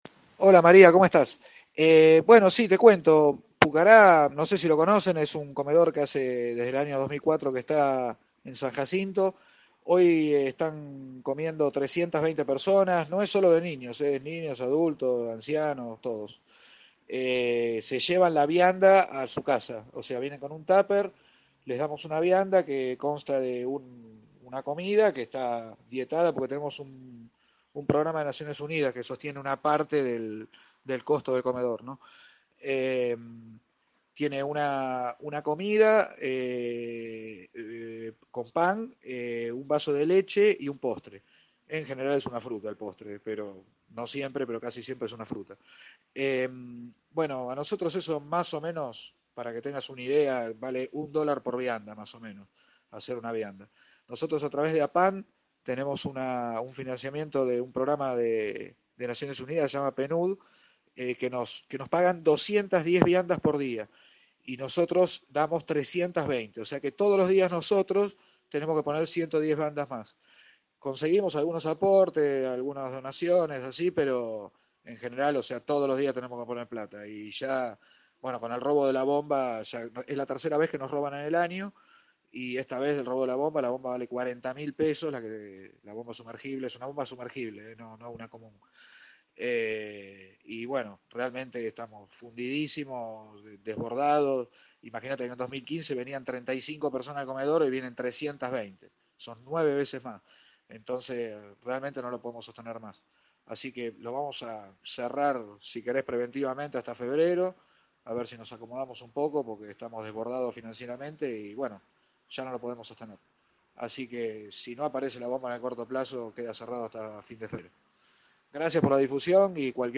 habló sobre ello con Bien Despiertos, programa emitido de 7 a 9, por radio De la Azotea